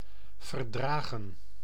Ääntäminen
IPA: /vərˈdraːɣə(n)/